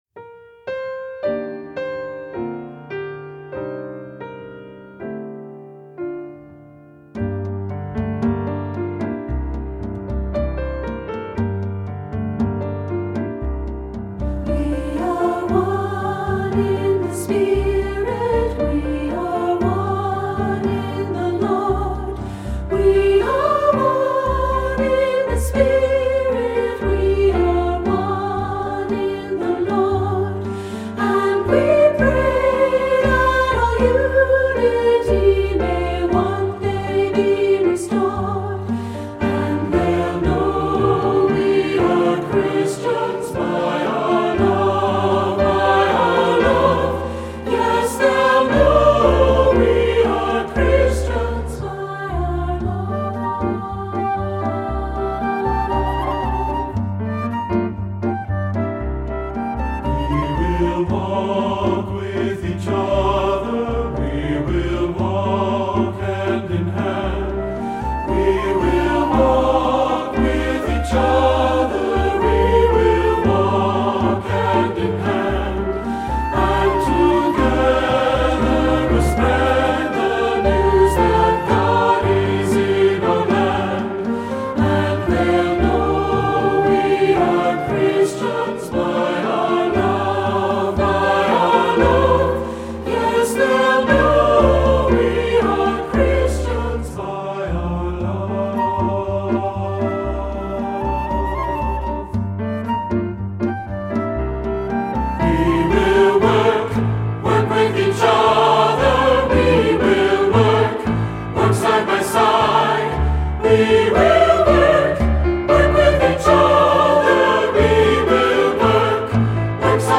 Voicing: 2-Part Mixed